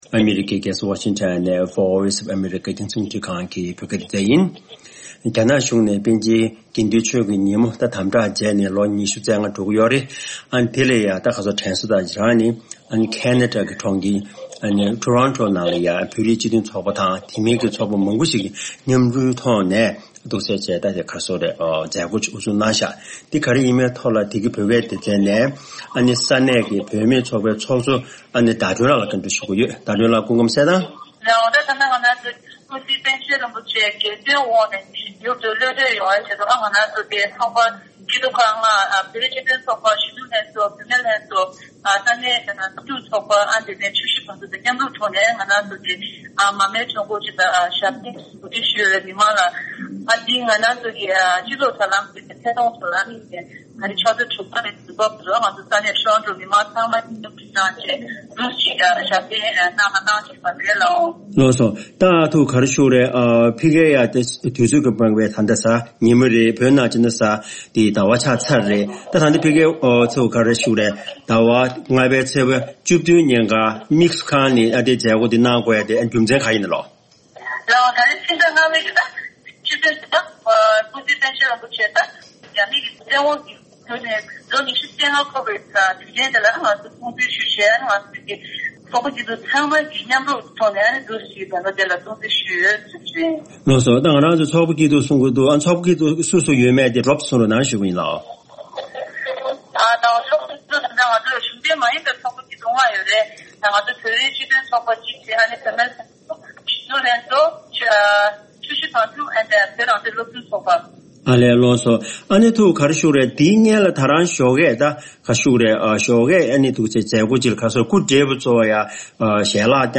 བཅར་འདྲི